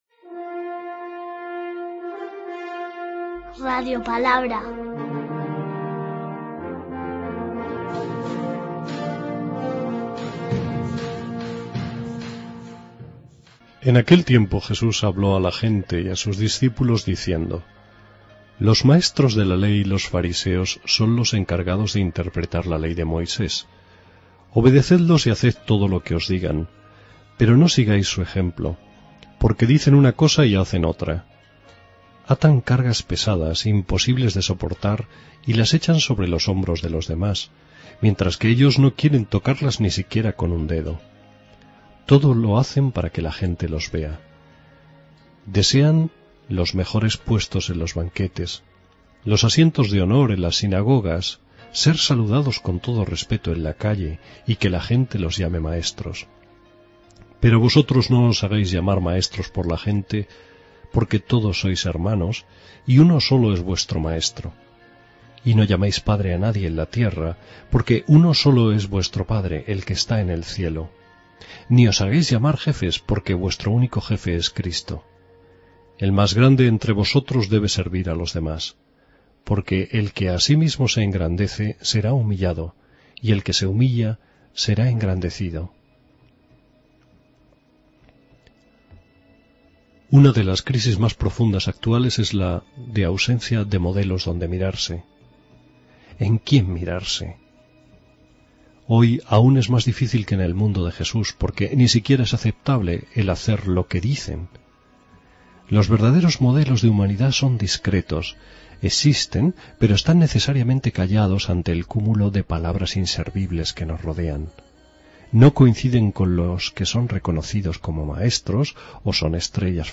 Lectura del santo evangelio de hoy según san Mateo 23,1-12